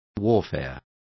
Complete with pronunciation of the translation of warfare.